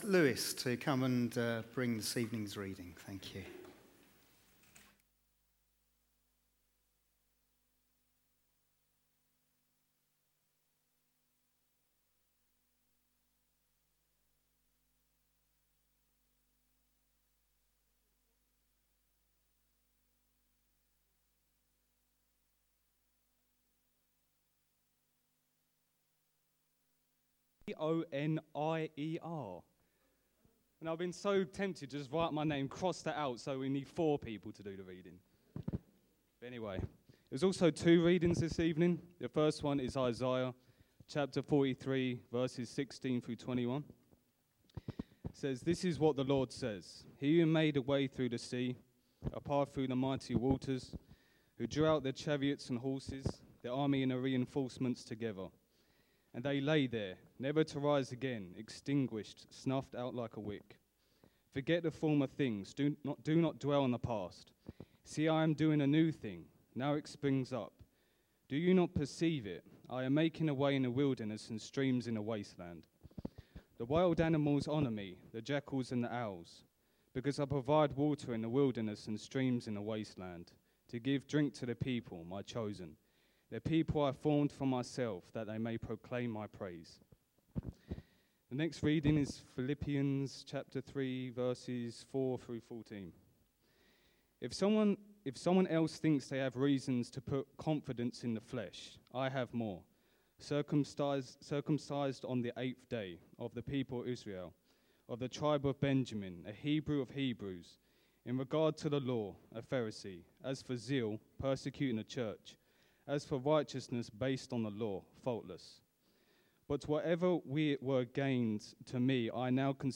A message from the series "LENT."